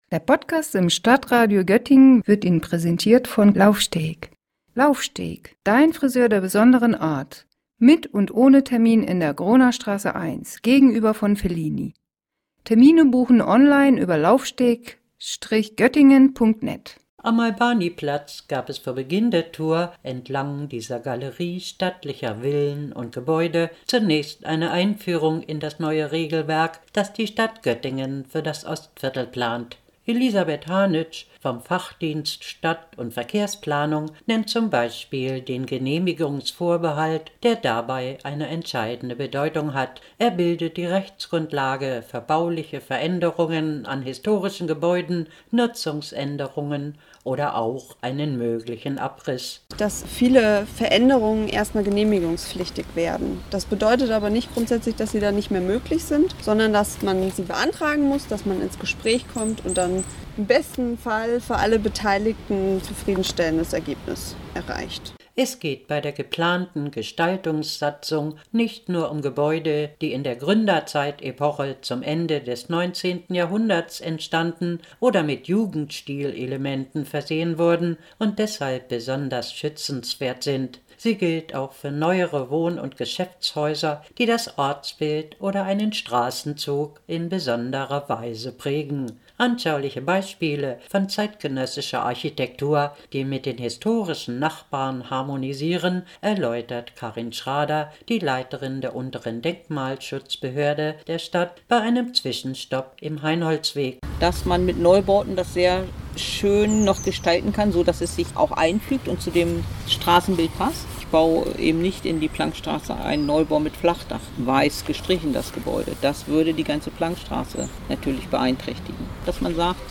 Sie betrifft das gesamte Ostviertel, in dem bereits eine Reihe von Gebäuden unter Denkmalschutz stehen und die Planckstraße als besonderes städtebauliches Ensemble. Anschaulich gemacht wurde die Bedeutung der geplanten Gestaltungserhaltungssatzung bei einem Sonntagsspaziergang durch das Ostviertel.